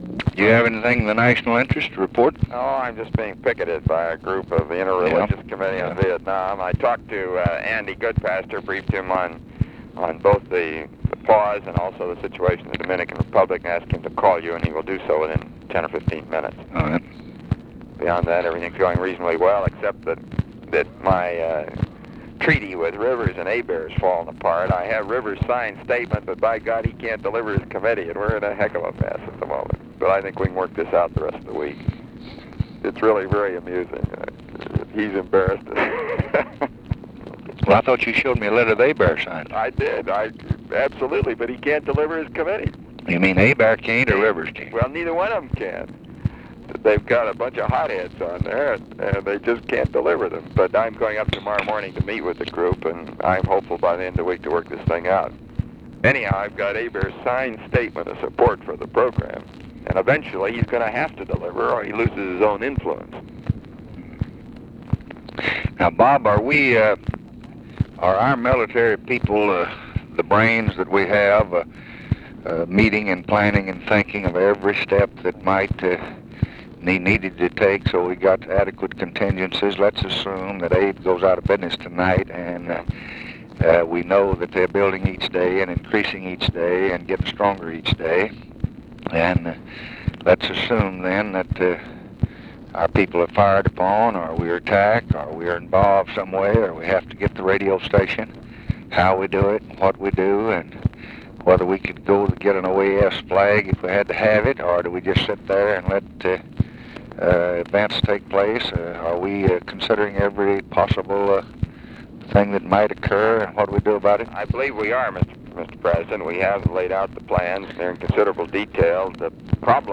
Conversation with ROBERT MCNAMARA, May 12, 1965